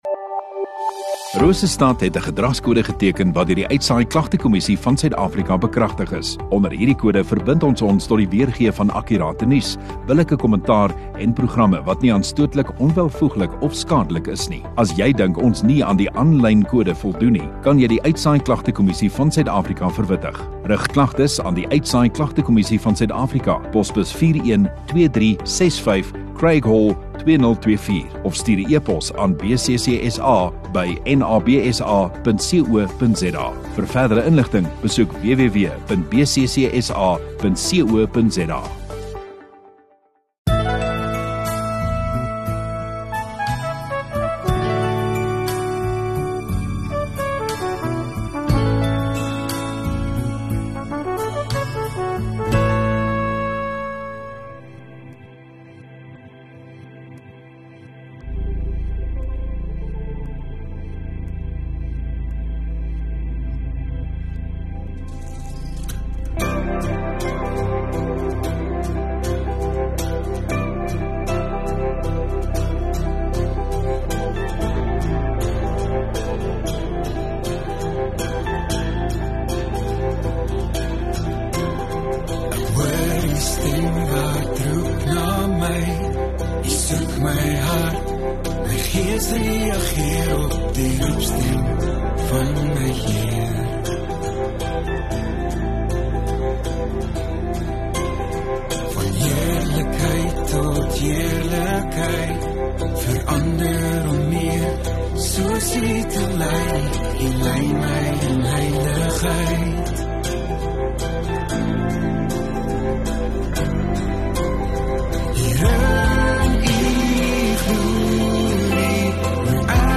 9 Dec Maandag Oggenddiens